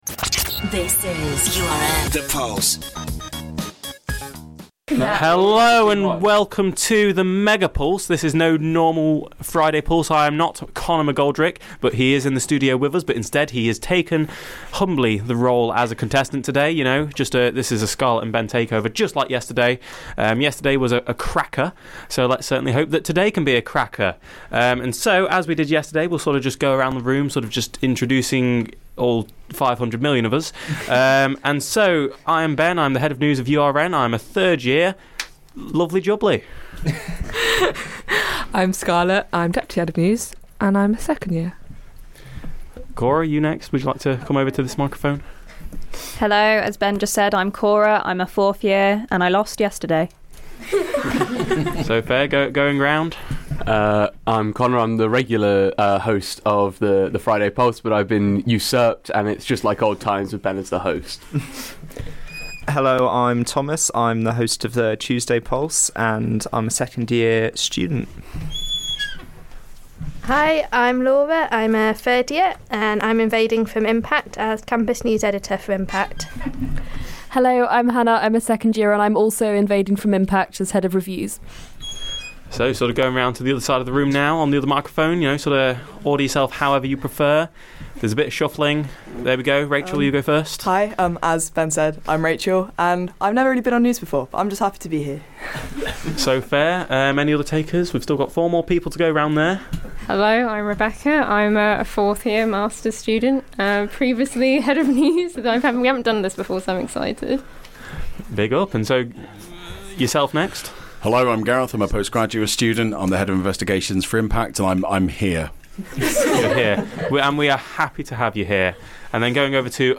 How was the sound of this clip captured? With this many people in the studio, it makes for a chaotic evening!